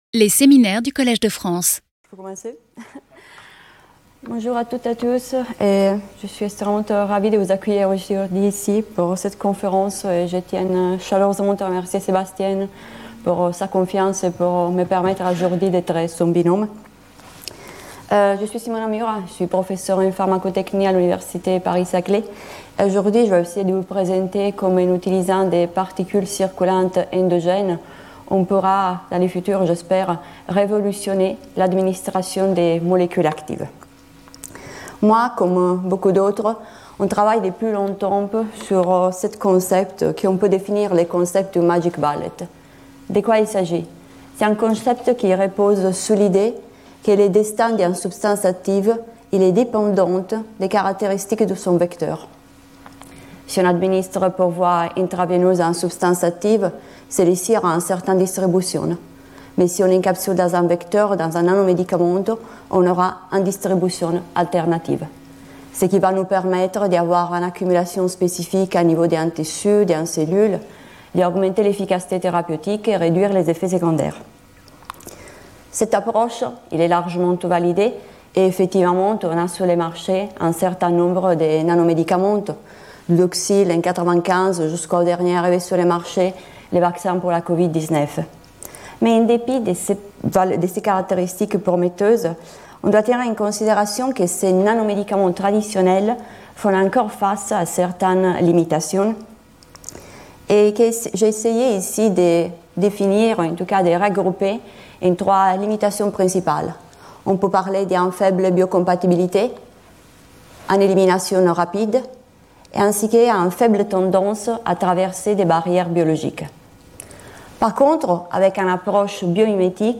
Lors de ce séminaire, je ferai le point sur les avancées récentes dans ce domaine.